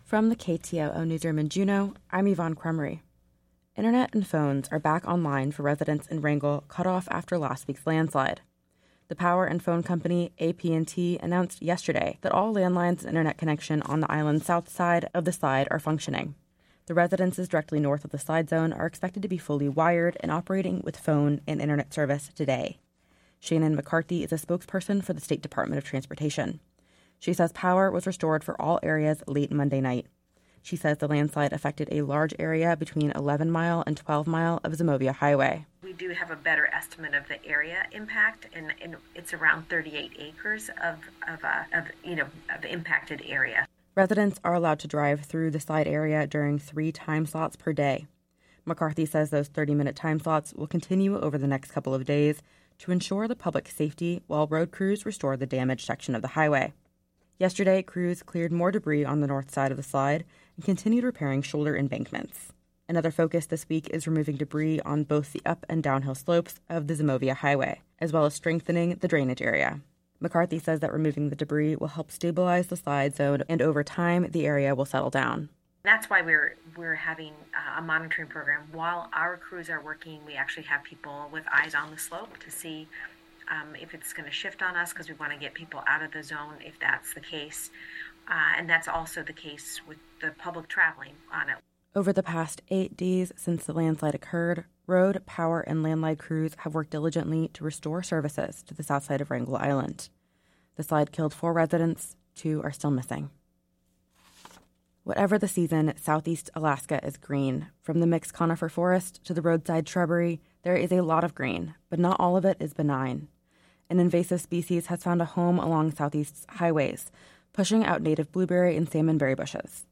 Newscast – Wednesday, Nov. 29, 2023